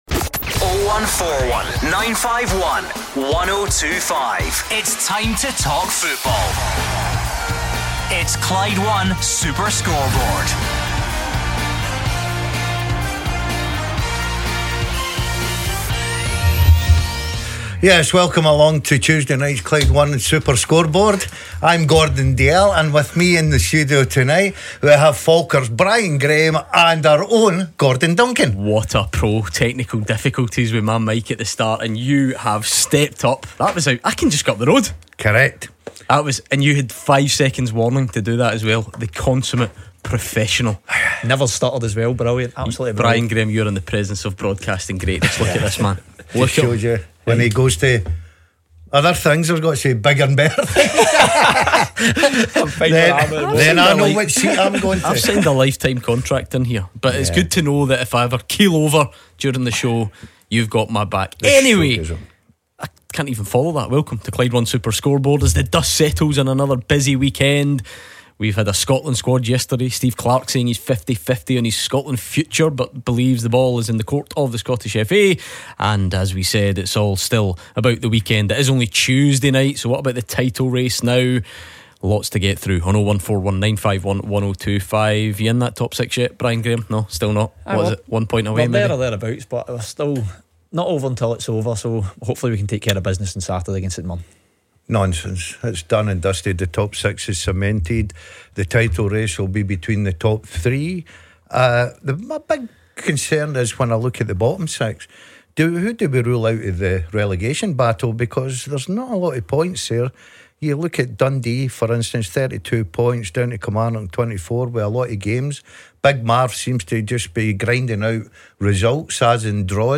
Taking the listeners calls